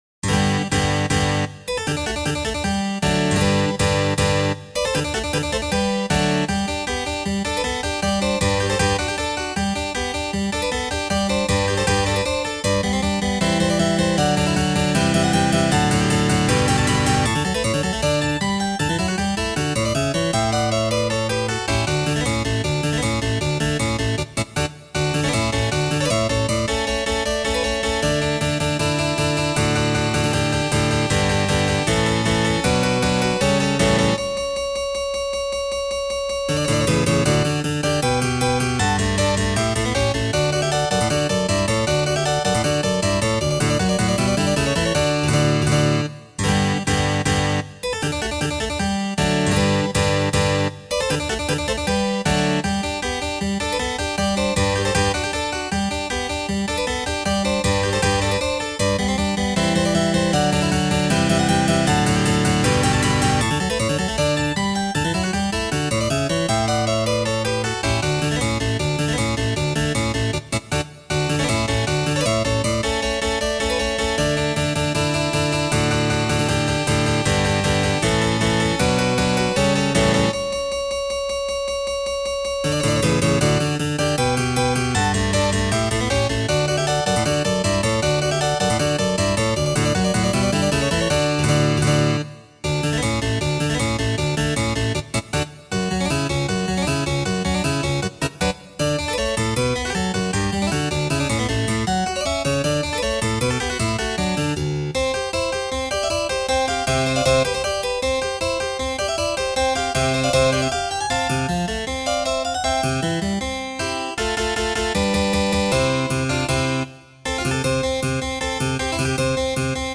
Overture in G - Piano Music, Solo Keyboard - Young Composers Music Forum